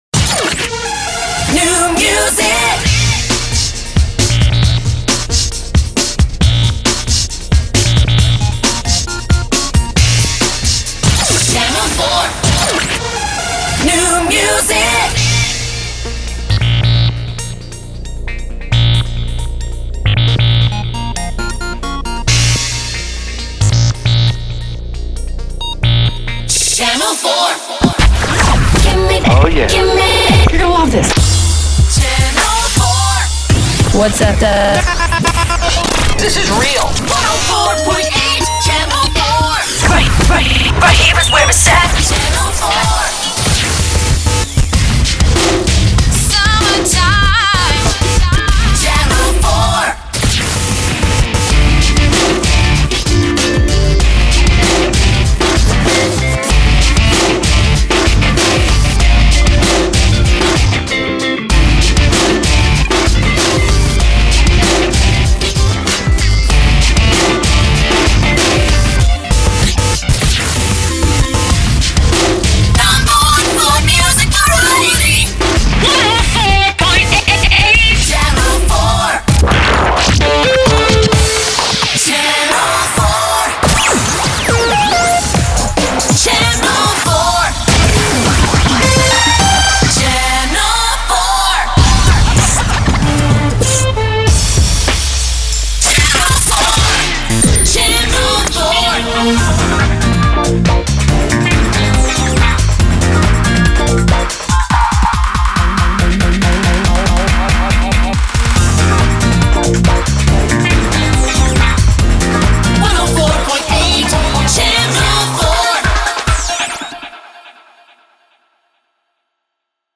Theme 35 >>> Four ultra-quick and really cool shotguns!
SweepIDs 20, 21, 22 >>> Another three sweepIDs for ya!